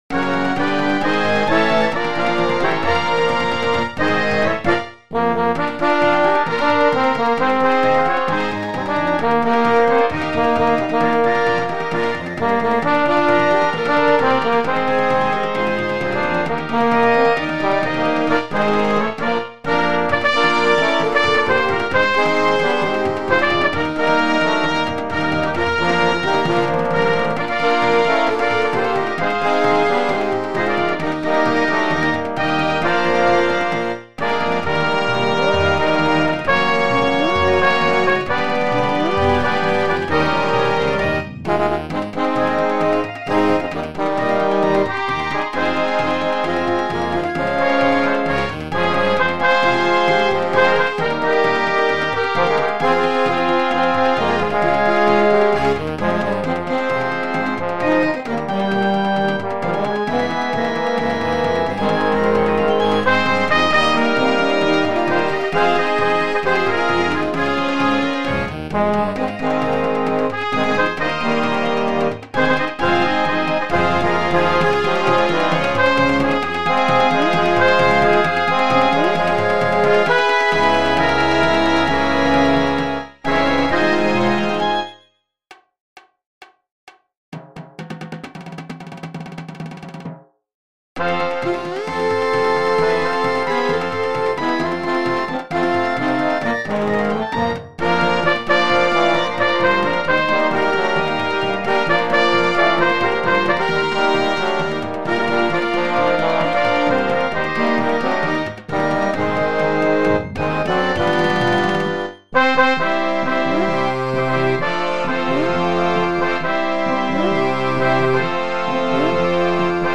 2025 Laker Marching Band Halftime Shows